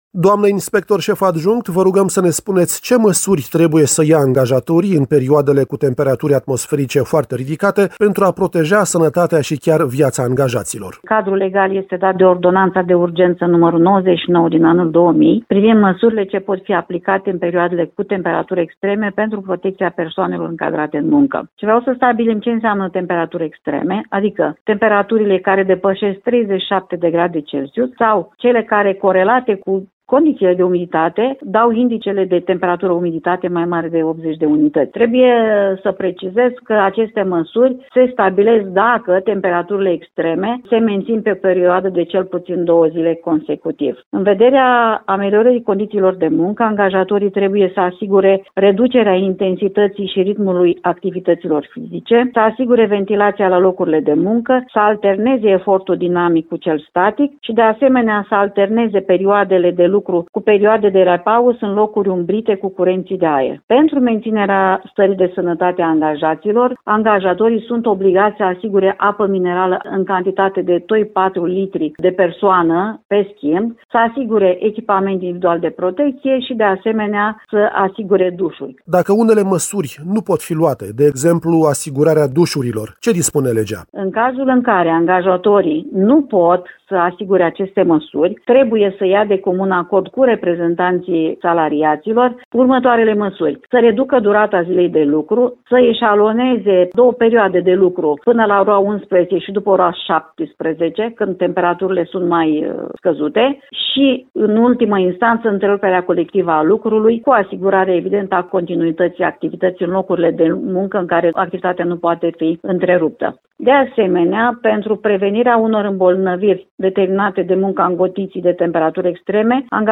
în interviul următor